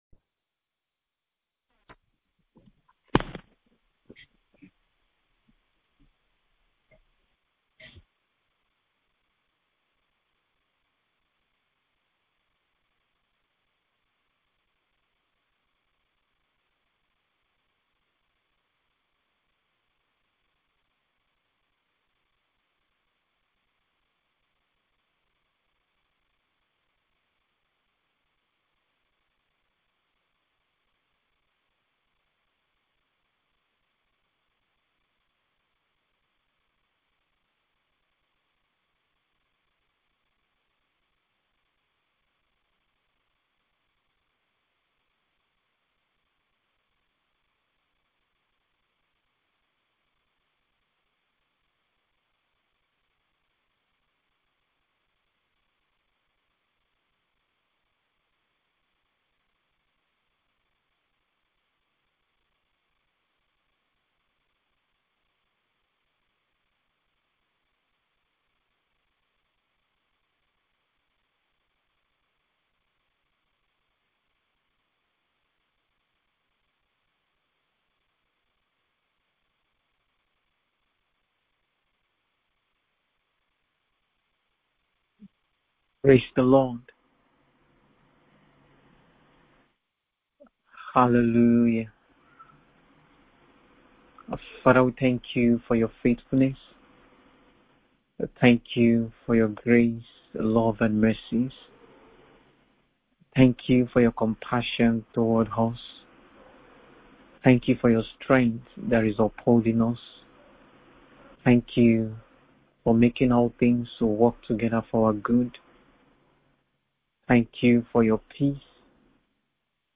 BIBLE STUDY CLASS